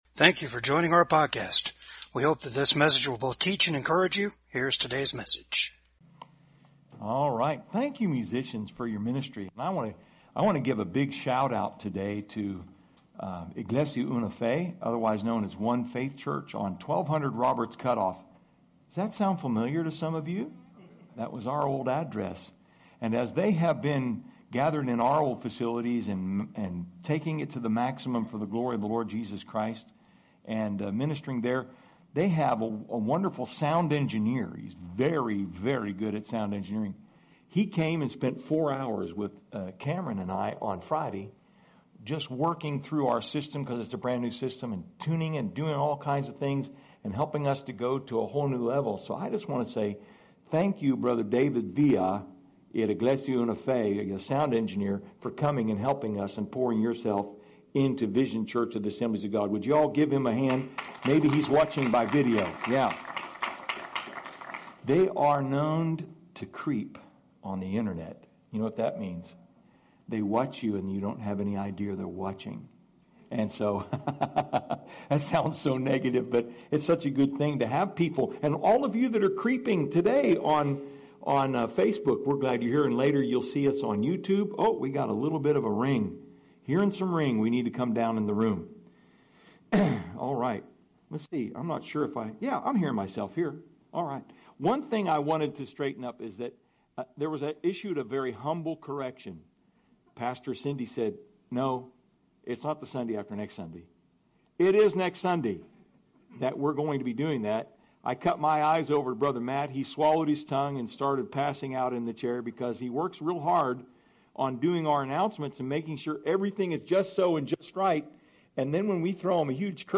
Psalms 23 Service Type: VCAG SUNDAY SERVICE 6.